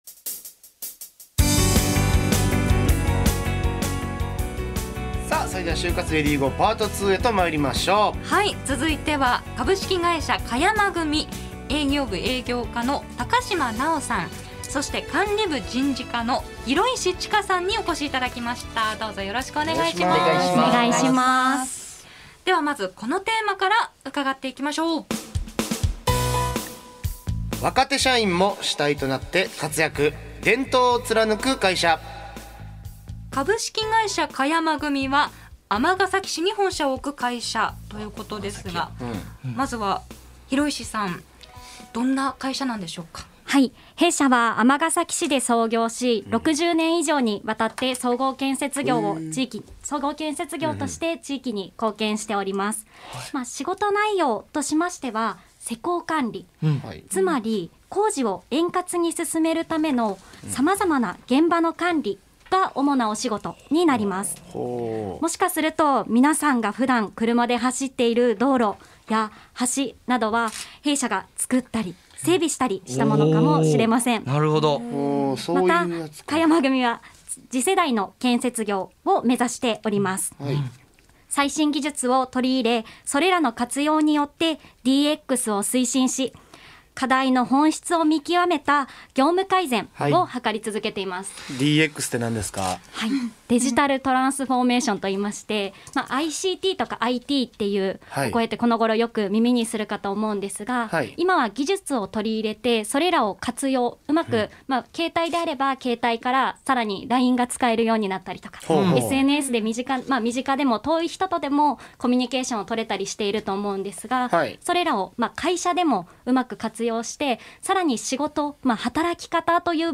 【放送音声】『ネイビーズアフロのレディGO！